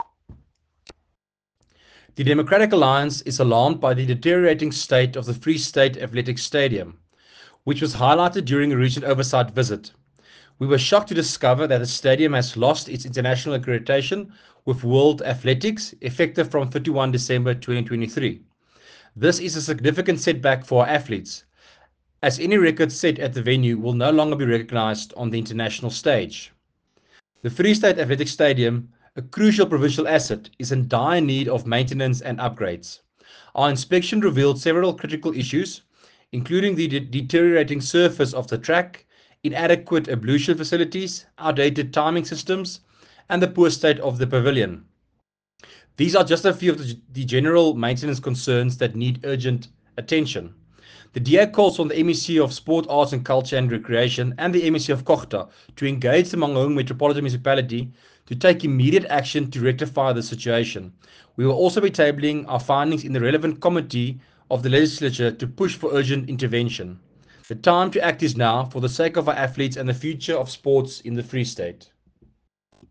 Afrikaans soundbites by Werner Pretorius MPL and images, here, here, here and here